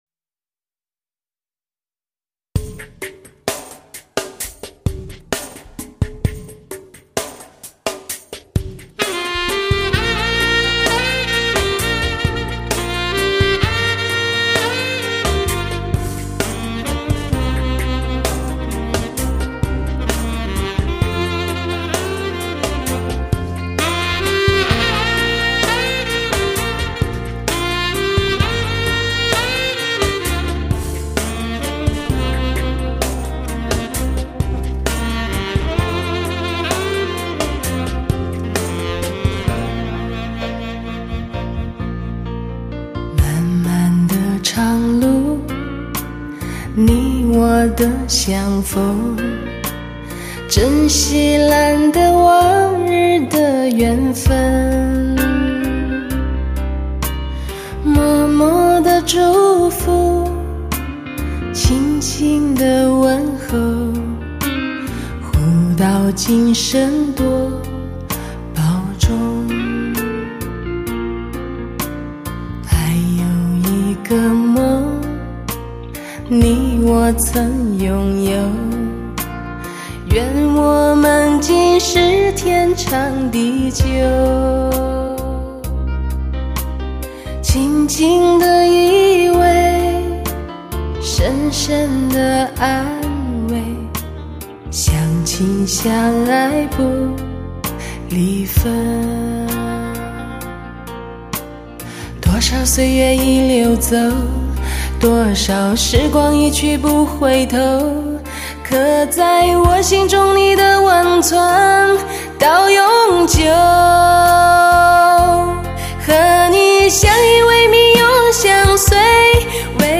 精确的乐器定位 低音强劲有力
中音清晰丰满 高音柔和圆滑
层次分明 声场辽阔  黄金母盘 德国制作